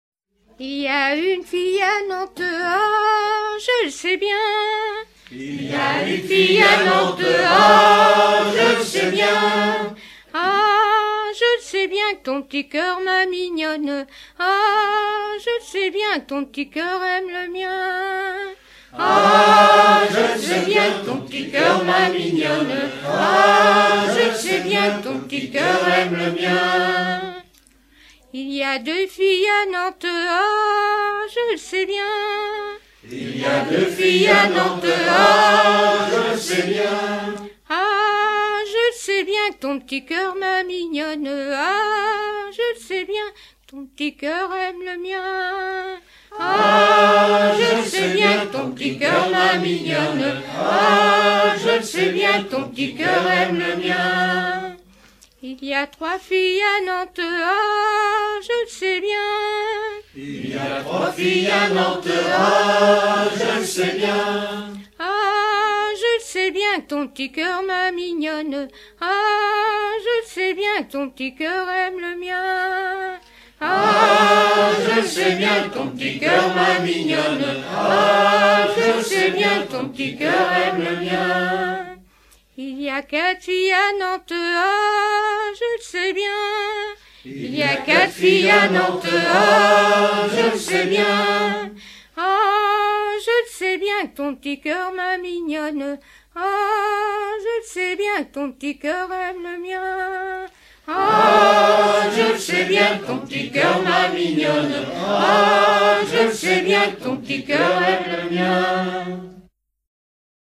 Genre énumérative
Pièce musicale éditée